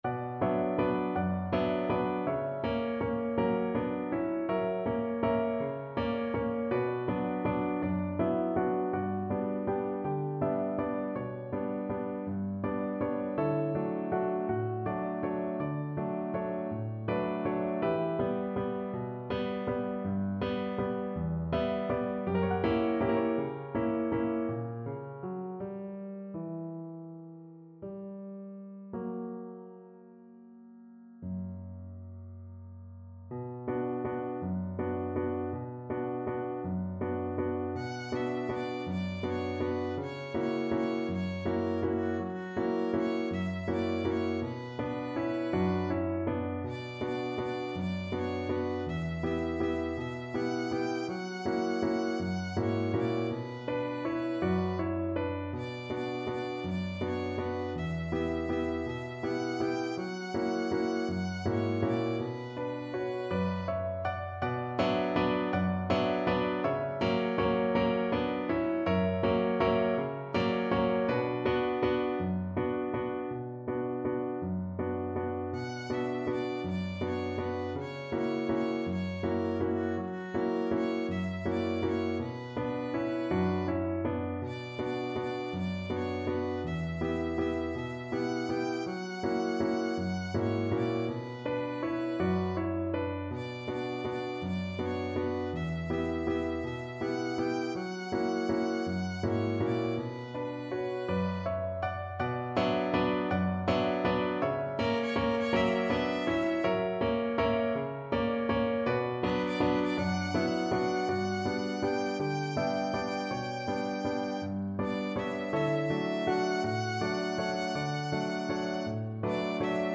3/4 (View more 3/4 Music)
One in a bar .=c.54
Classical (View more Classical Violin Music)